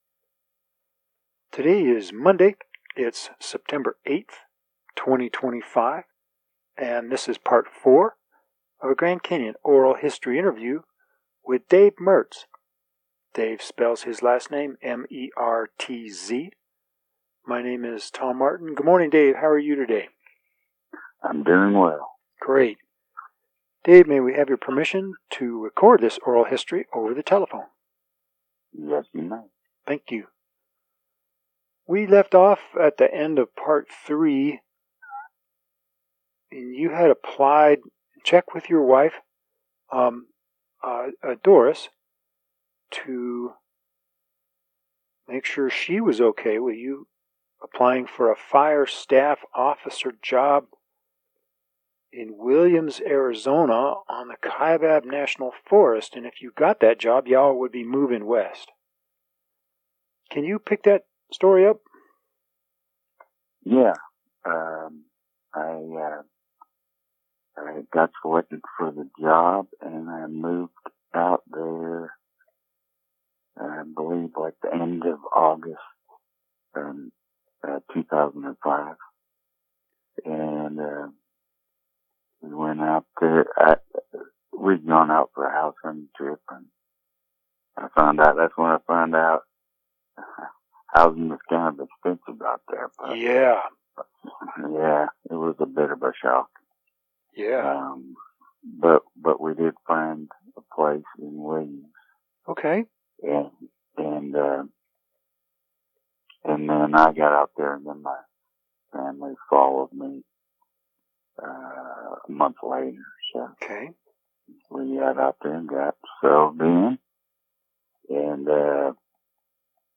Oral Histories for last name beginning with M